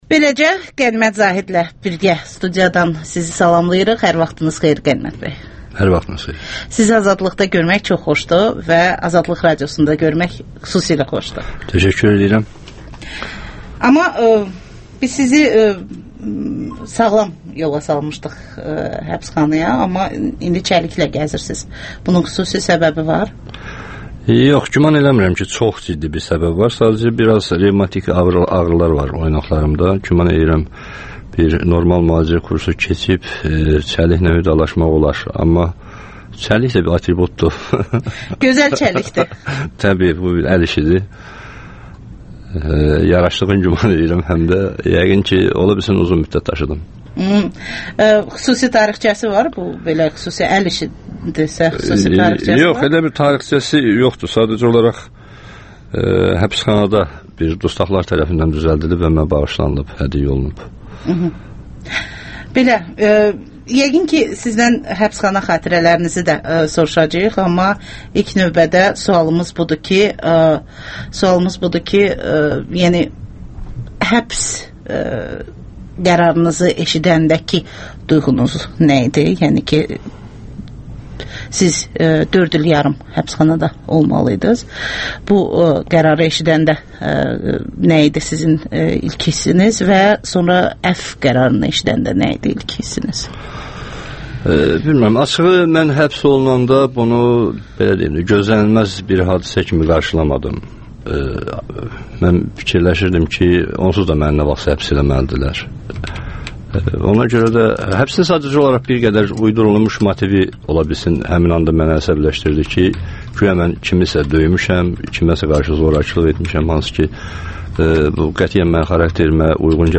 Qənimət Zahidlə müsahibə - 1-ci hissə